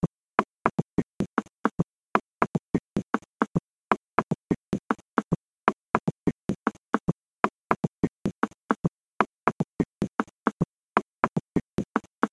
Inciso 4 – Mambo